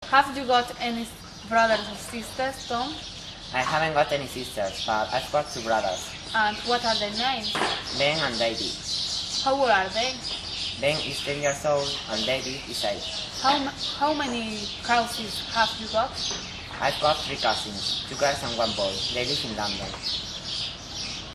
Everyday conversations
Chico y chica de pié una frente a otro en zona de jardín mantienen una conversación
Chico y chica charlando en jardín.